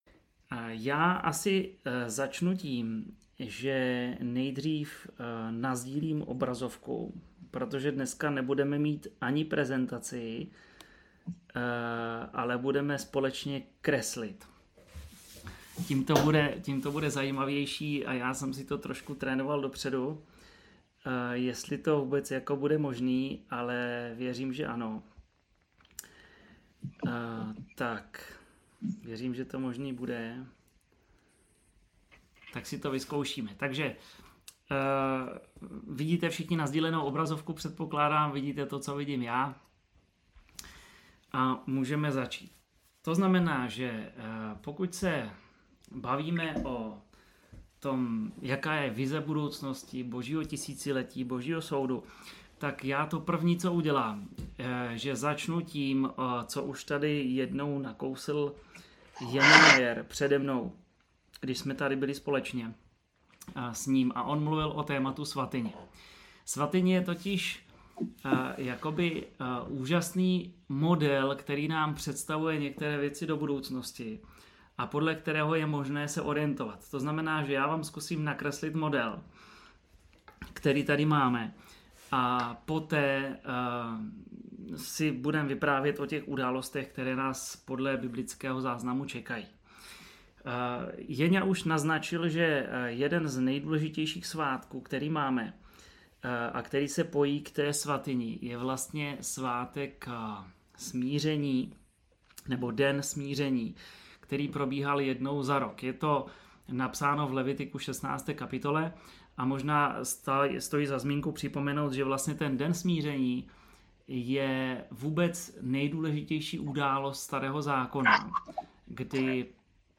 Šestá přednáška z cyklu ČEMU VĚŘÍ ADVENTISTÉ